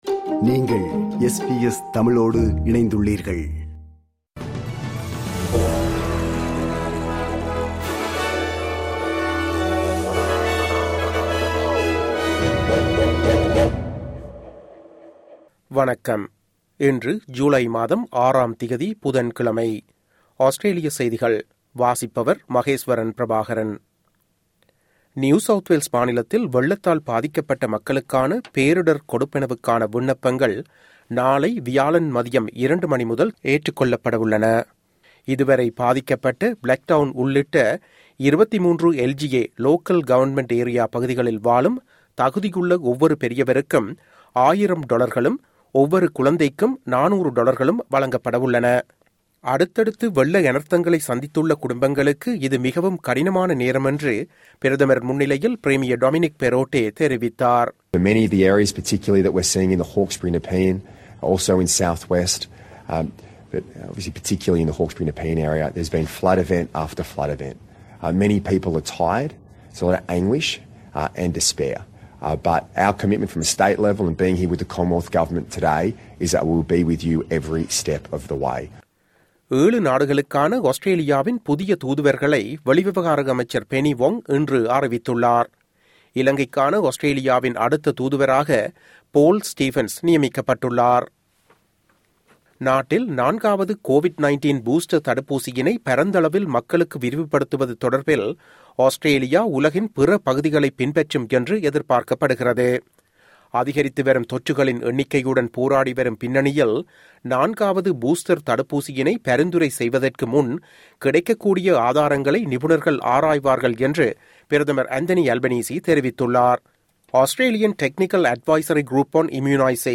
Australian news bulletin for Wednesday 06 July 2022.